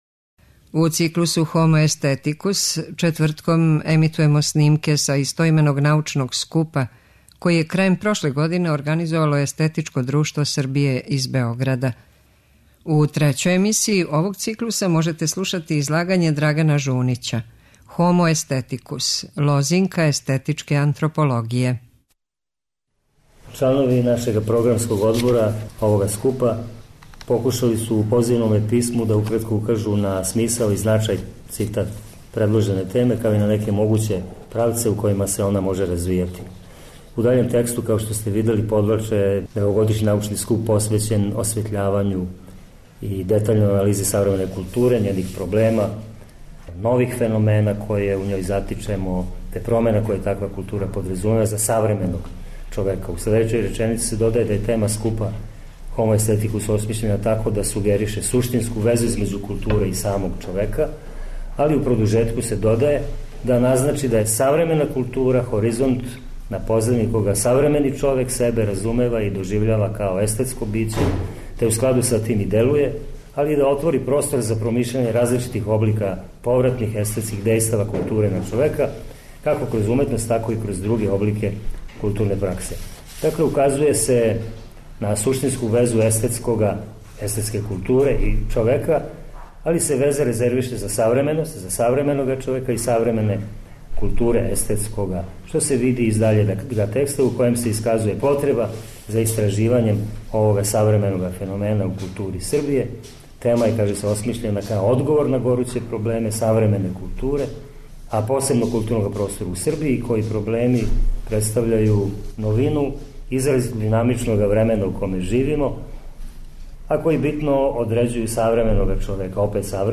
У циклусу HOMO AESTHETICUS четвртком ћемо емитовати снимке са истоименог научног скупа који је, у организацији Естетичког друштва Србије, одржан 22. и 23. децембра у Заводу за проучавање културног развитка у Београду.
Научни скупoви